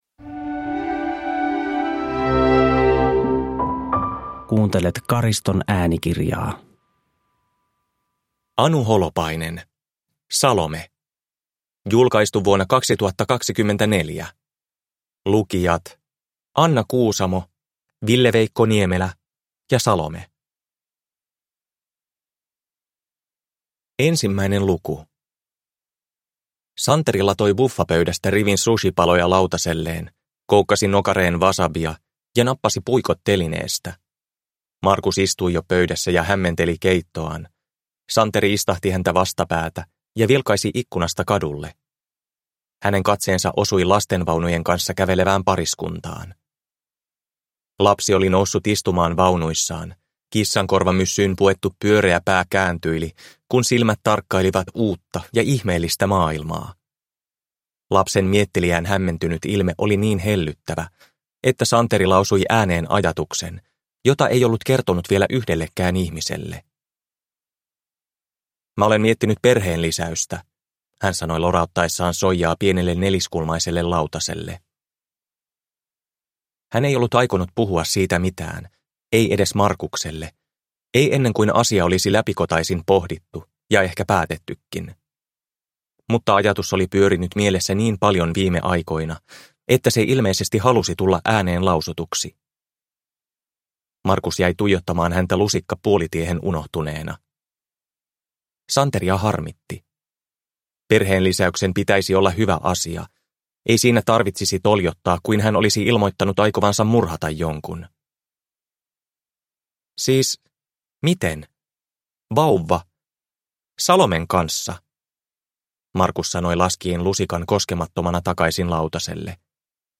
Salome (ljudbok) av Anu Holopainen | Bokon